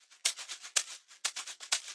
循环播放 " 敲击乐录制的振动器 123 bpm
描述：简单的振动器循环。循环在123 bpm。
Tag: 回路 最小 高科技 TECHNO 打击乐器 房子 滚筒 振动筛